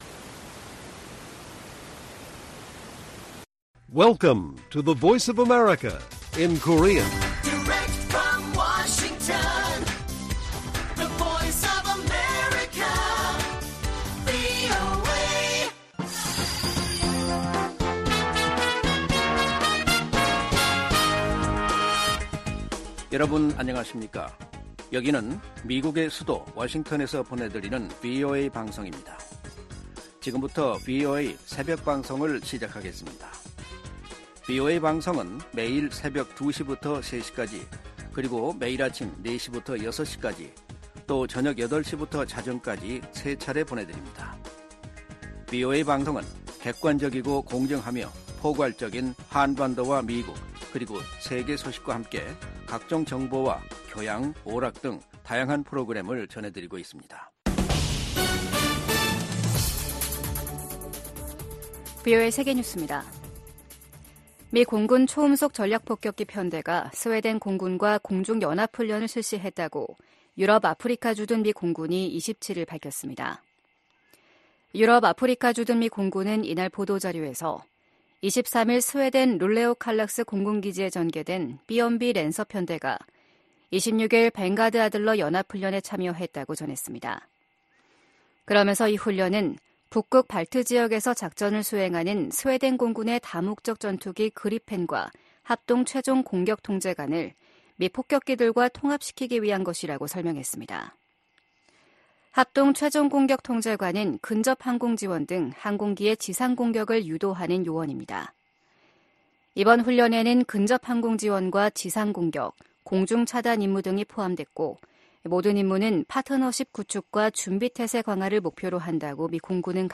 VOA 한국어 '출발 뉴스 쇼', 2024년 2월 29일 방송입니다.